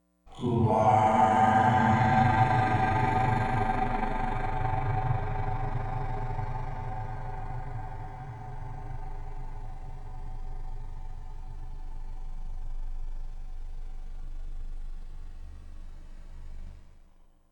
Processing: stereo + KS delay 543 (A); feedback 900, 1:1 then 9:1 on "are"